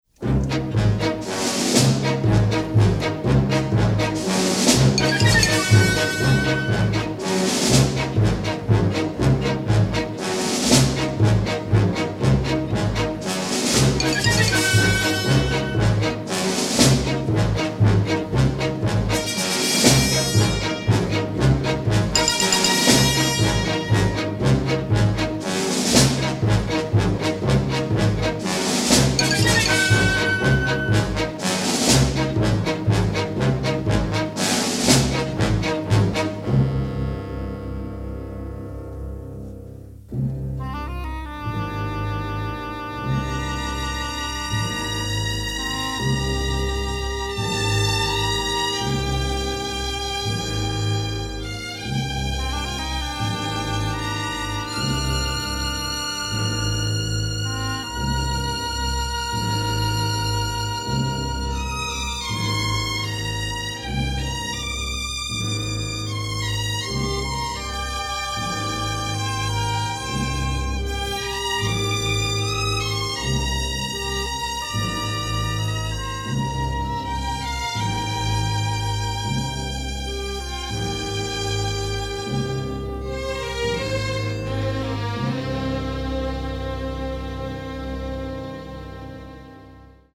rousing orchestral work